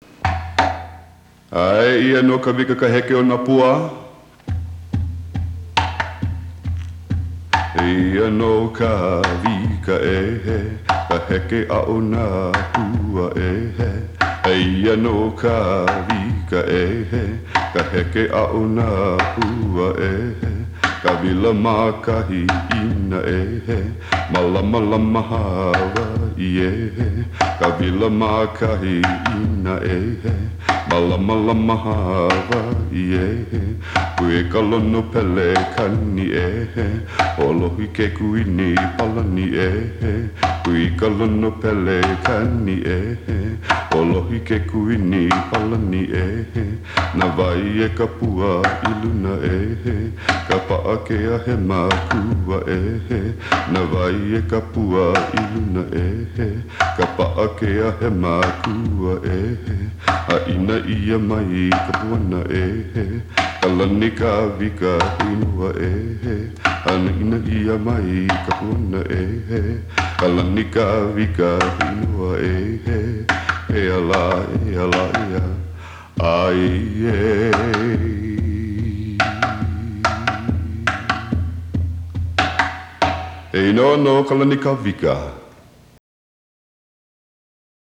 Hula - Eigentlich ein Tanz veröffentlicht.
August 2011 um 20:05 Klingt gut und tanzbar.
05-Kawika_Kahiko-Style.m4a